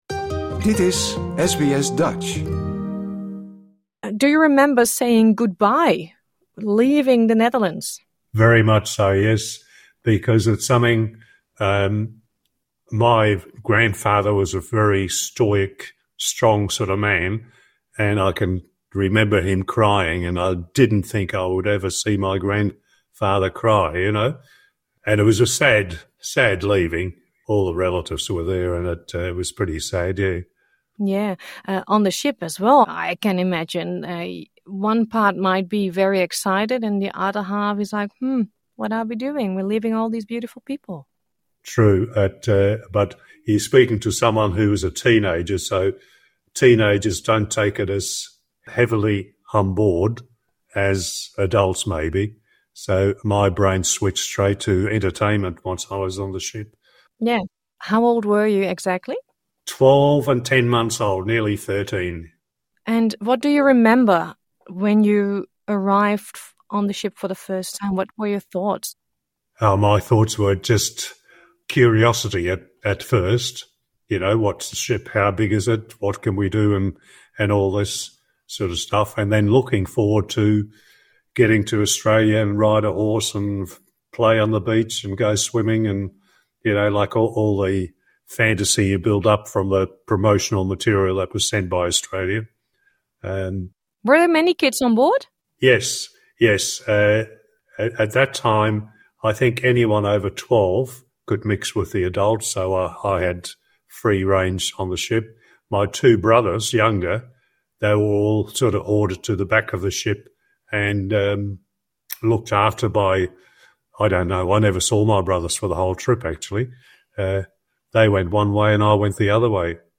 This interview is in English.